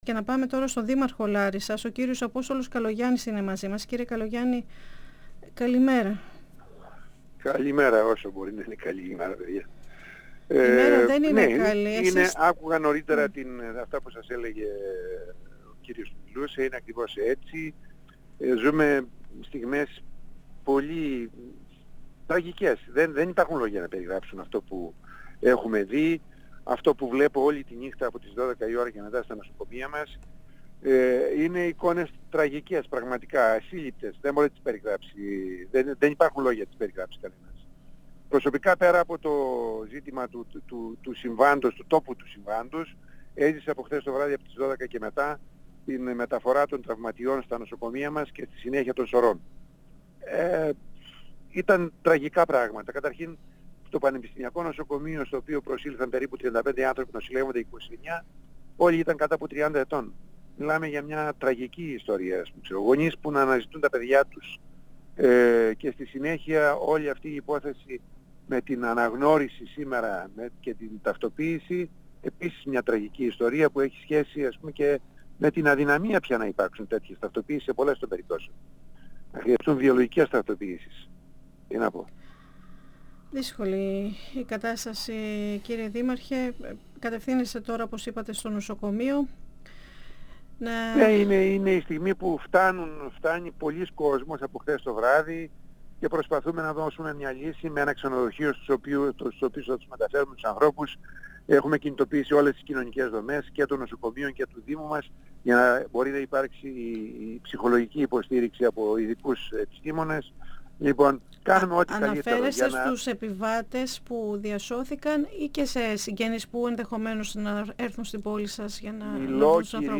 O δήμαρχος Λάρισας Απόστολος Καλογιάννης στο σημείο της τραγωδίας